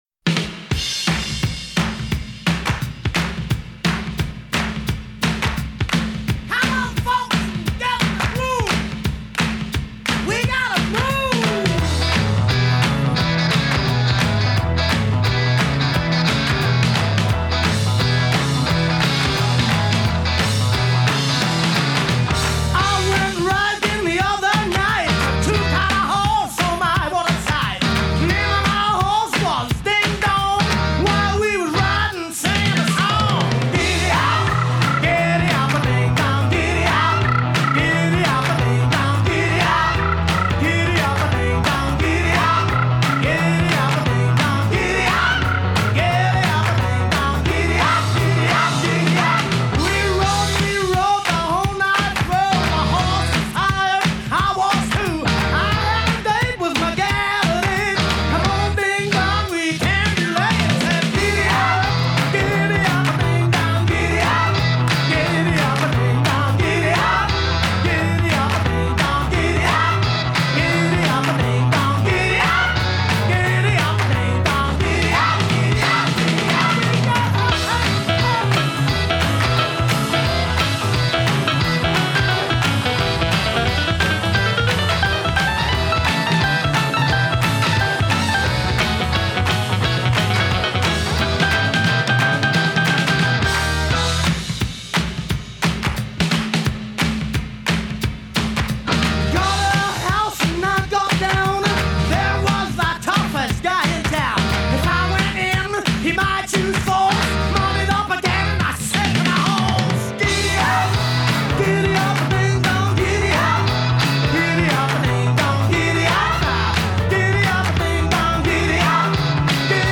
Жанр: Blues Rock